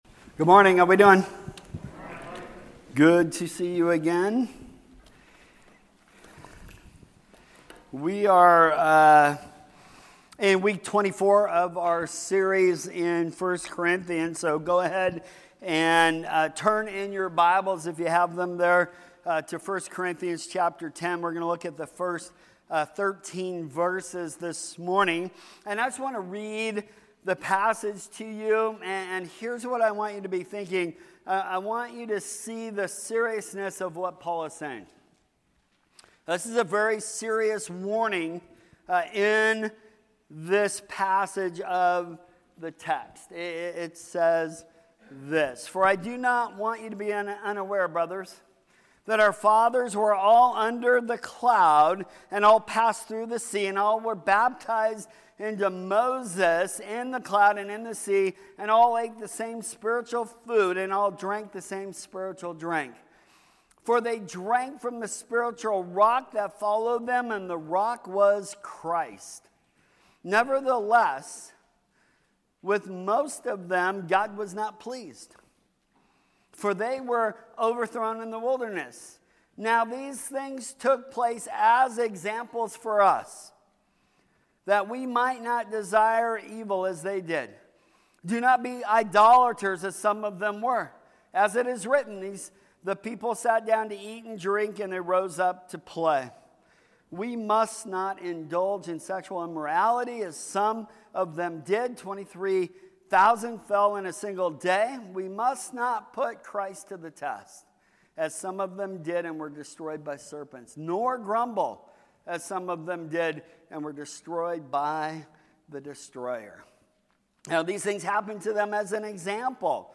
Sermons | New Creation Fellowship